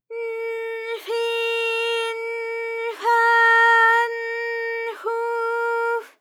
ALYS-DB-001-JPN - First Japanese UTAU vocal library of ALYS.
f_n_fi_n_fa_n_fu_f.wav